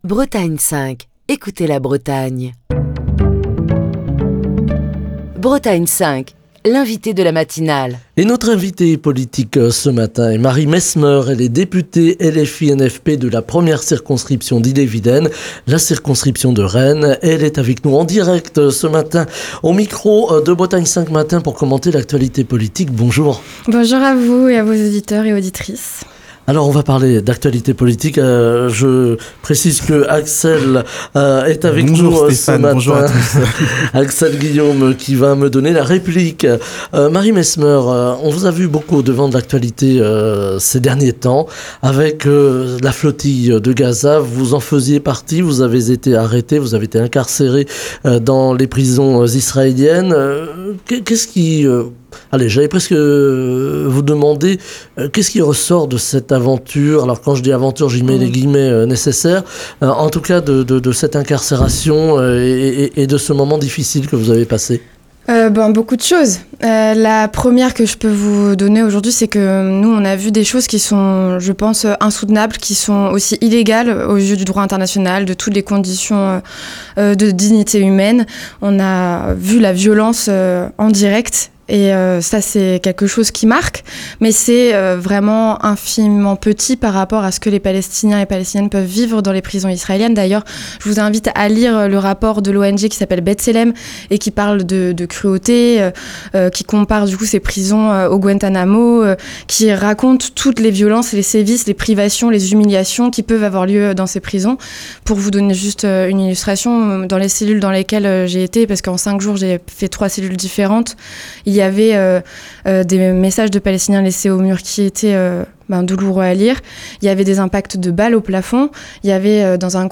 Marie Mesmeur, députée LFI-NFP de la 1ère circonscription d’Ille-et-Vilaine (Rennes), était l'invitée politique de la matinale de Bretagne 5, ce jeudi.